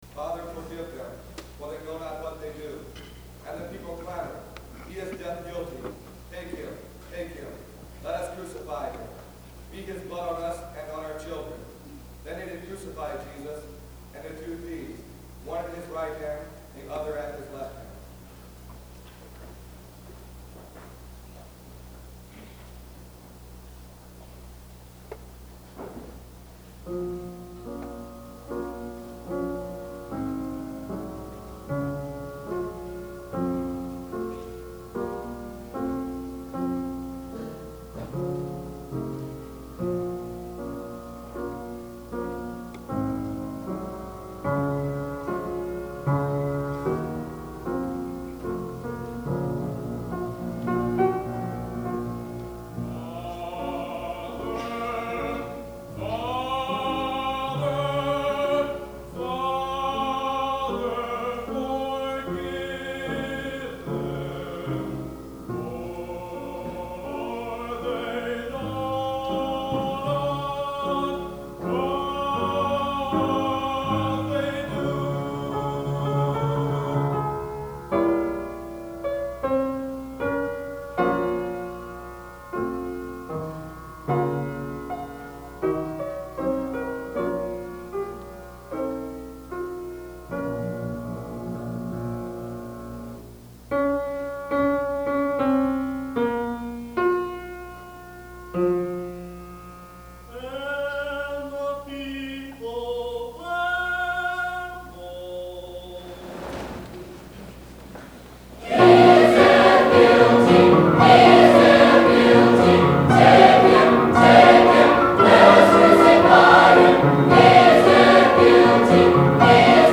Narrator
Soporano